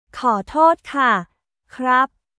コートート・カ／クラップ